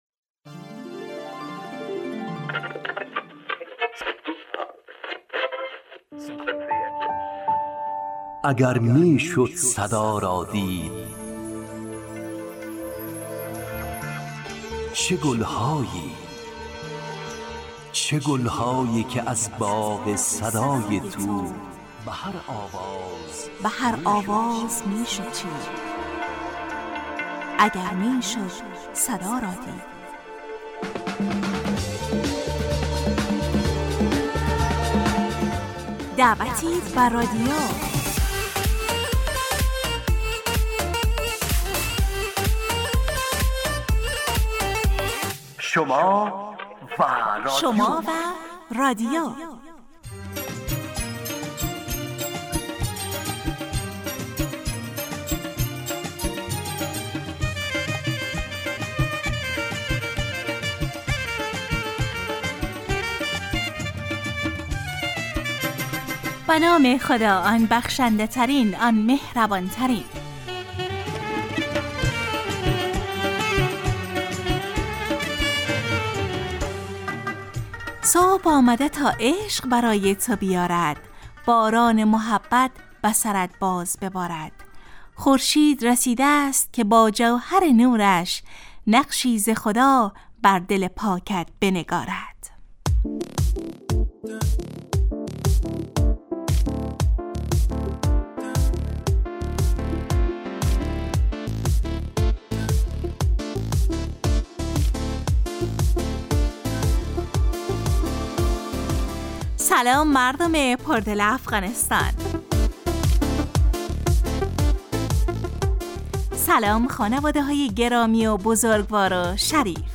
گفتگو با همکار رادیو دری